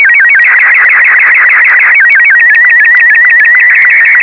SSTV : Slow Scan TeleVision, Télévision à balayage lent
Robot 36
SSTVR36.wav